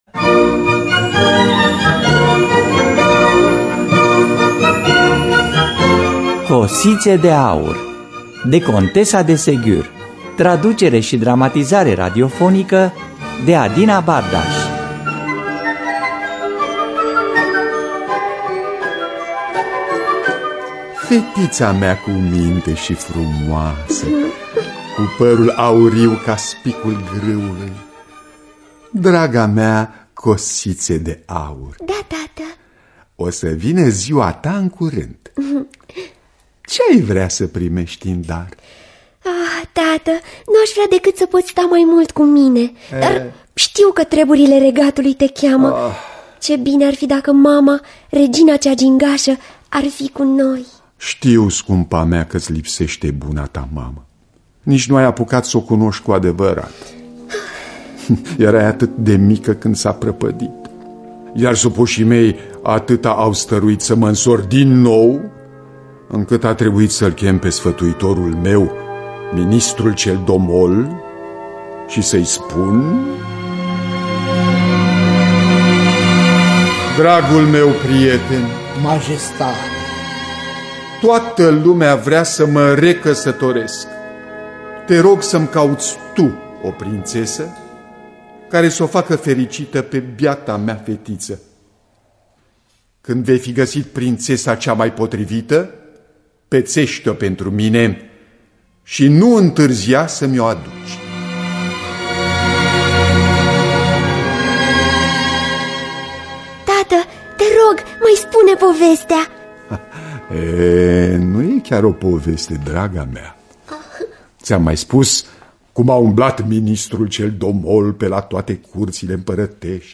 Sophie Rostopchine Segur (Contesa de) – Cosite De Aur (2013) – Teatru Radiofonic Online